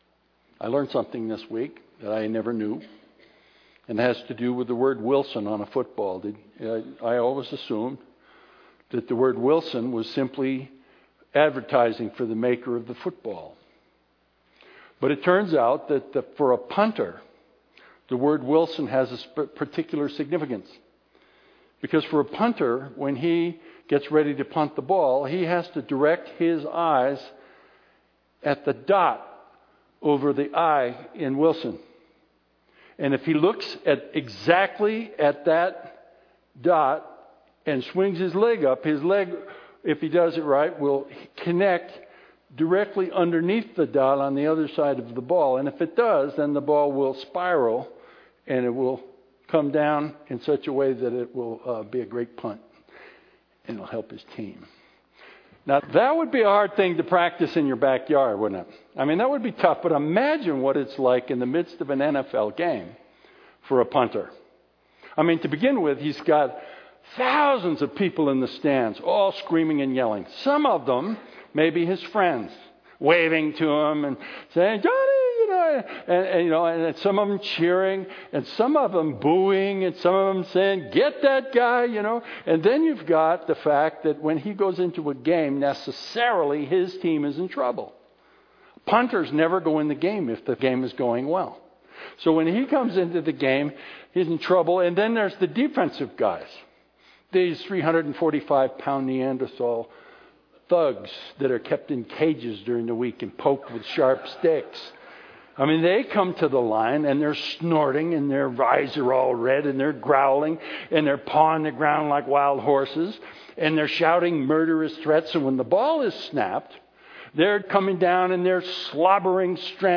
Jesus told a parable about what it means, recorded in the 12th chapter of Luke, starting at verse 35.  Let’s take a close look at what that parable means and consider how it applies to our lives today, once again by means of a recorded message.